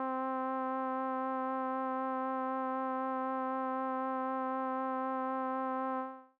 Lead (Glitter).wav